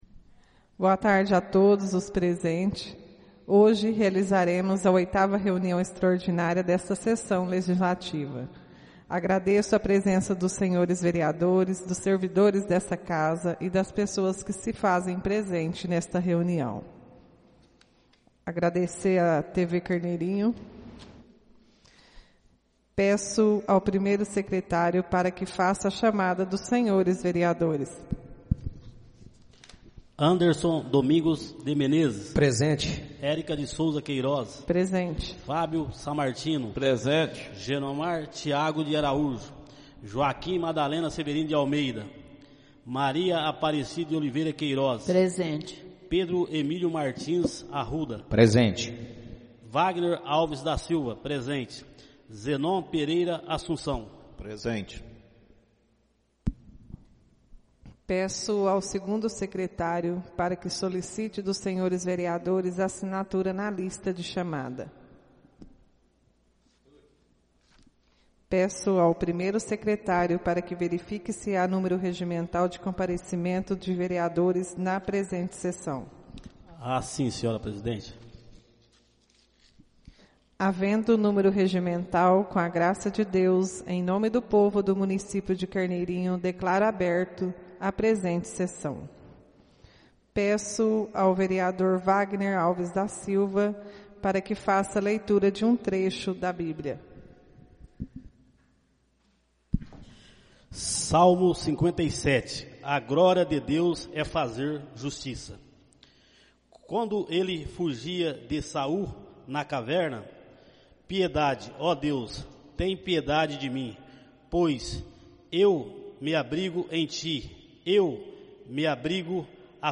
Áudio da 08.ª reunião extraordinária de 2022, realizada no dia 22 de Agosto de 2022, na sala de sessões da Câmara Municipal de Carneirinho, Estado de Minas Gerais.